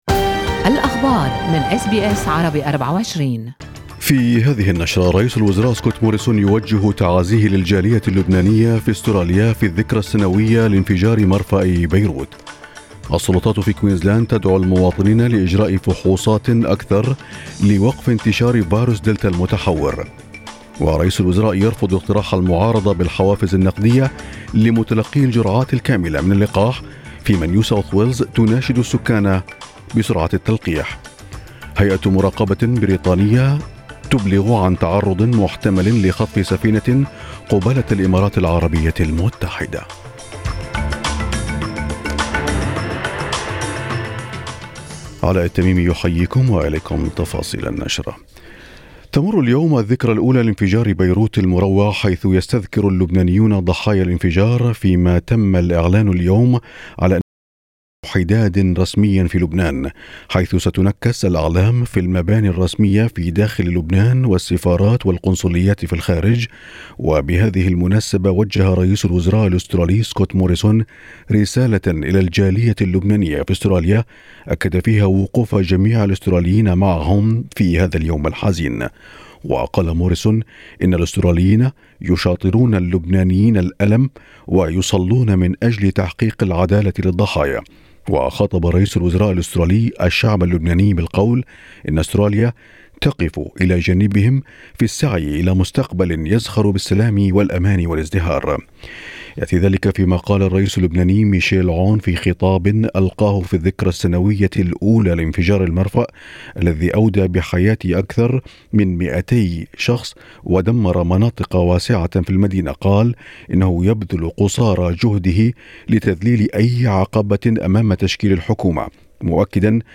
نشرة أخبار الصباح 4/8/2021